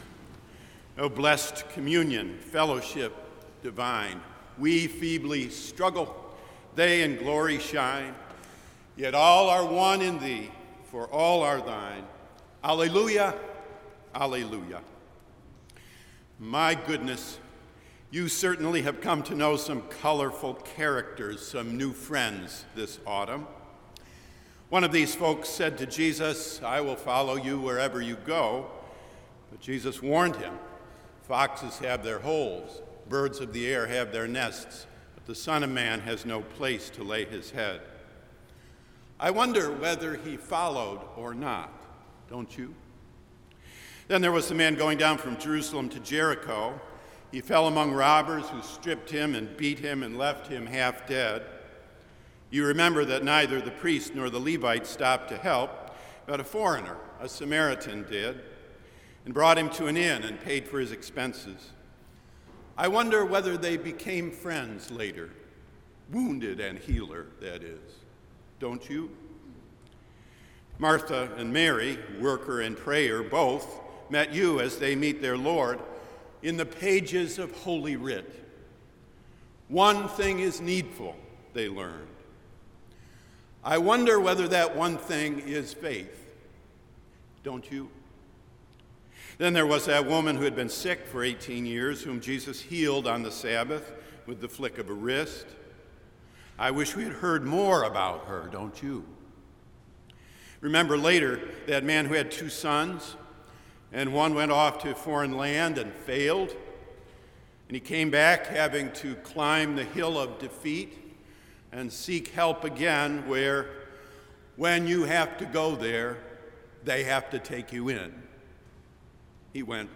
Click here to hear the full service